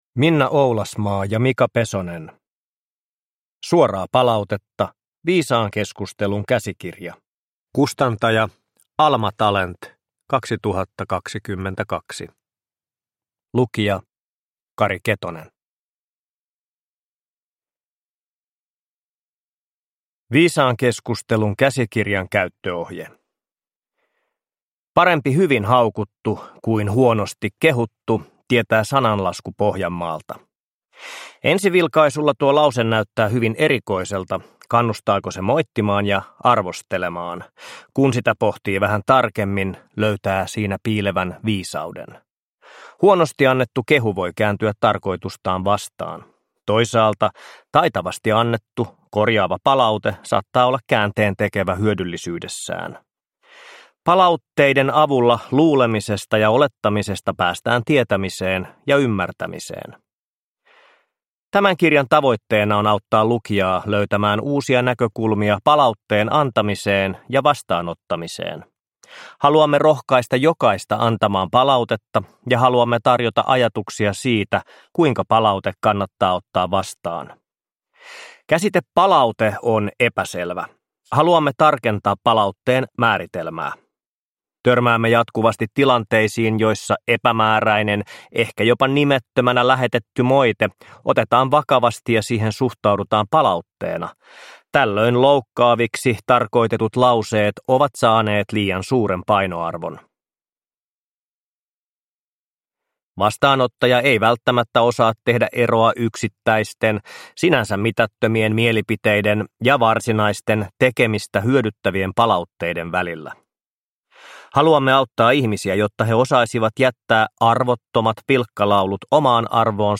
Suoraa palautetta! – Ljudbok – Laddas ner